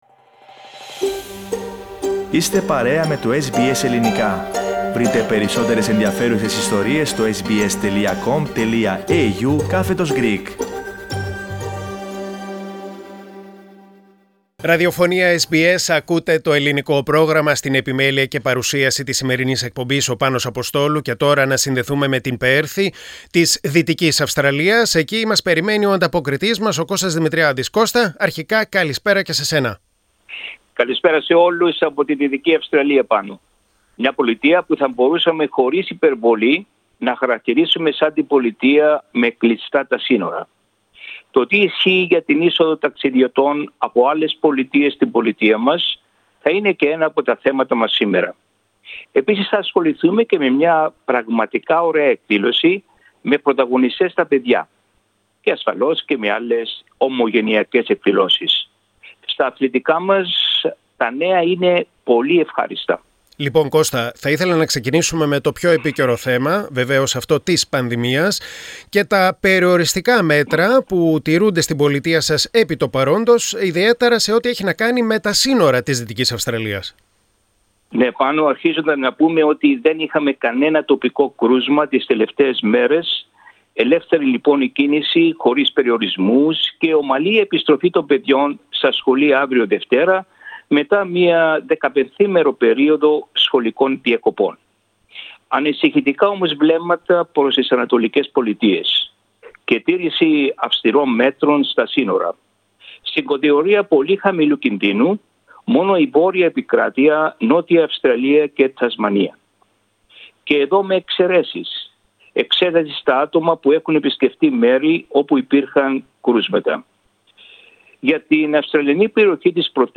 Ειδήσεις και νέα από την ομογένεια της Δυτικής Αυστραλίας στην εβδομαδιαία ανταπόκριση από την Πέρθη.